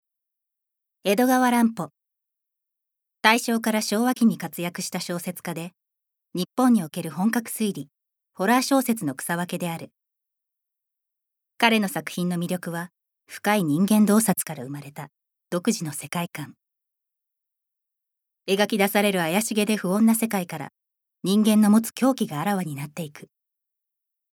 ボイスサンプル
ナレーション１